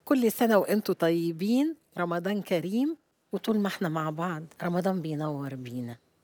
وعند فتح المكاملة الواردة، استمع عملاء ڤودافون لصوت نجمتهم المحبوبة في رسالة صوتية دافئة: “كل سنة وأنتم طيبين.. رمضان كريم.. وطول ما إحنا مع بعض.. رمضان بينور بينا”.